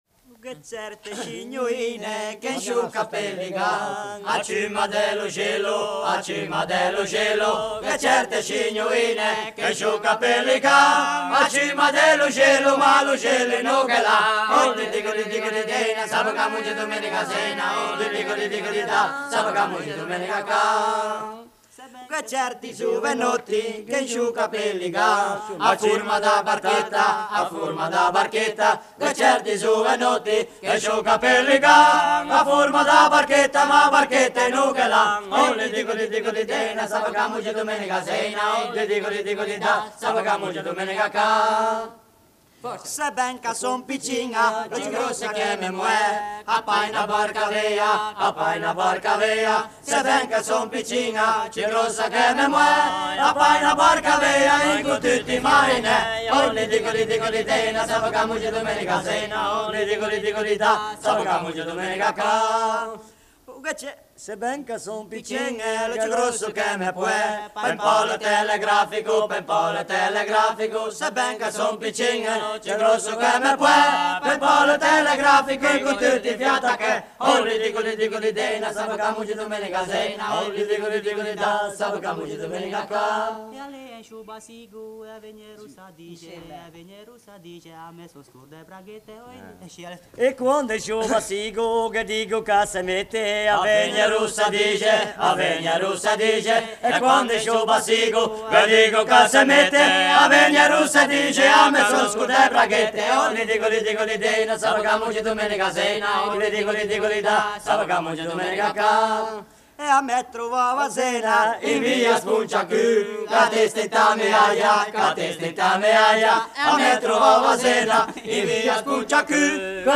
Come ho detto, Alan (che era accompagnato in Italia dall'emerito etnomusicologo Diego Carpitella) era innamorato della Liguria, ed ha effettuato una serie di approfondimenti in questa regione, a Genova, con i cori dei "trallalleri", ma soprattutto nel ponente ligure, dove è antichissima la tradizione del canto polifonico a cappella.
In calce a questo inutile pensierino, e con riferimento al disco di Bajardo, in cui è registrata una canzone popolare piuttosto famosa in tutta la Liguria, mi è capitato di pensare alla situazione, di immedesimarmi in questo americano studioso di musica etnica che approcciava un popolo sconosciuto come quello dell'entroterra ligure.
Così, hanno cantato gli stornelli più scurrili della tradizione, quelli con riferimenti non solo espliciti ma anche esagerati alla sfera sessuale.